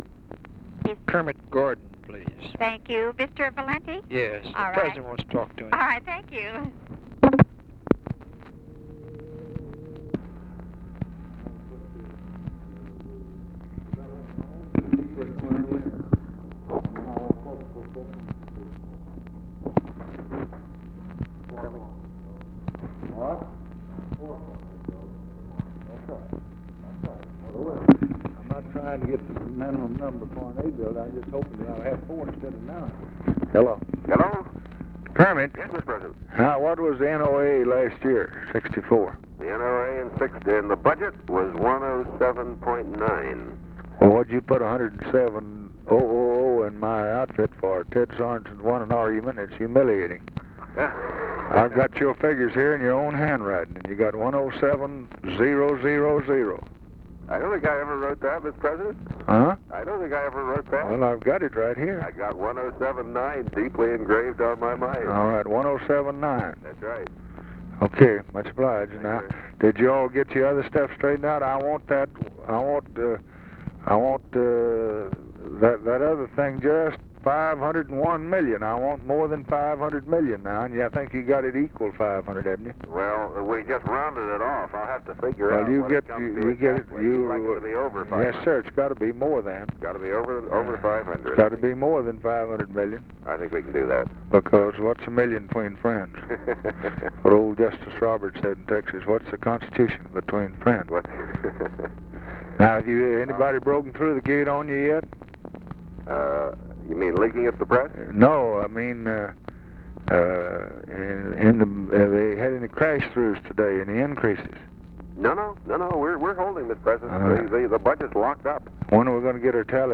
Conversation with KERMIT GORDON, JACK VALENTI and OFFICE CONVERSATION, January 7, 1964
Secret White House Tapes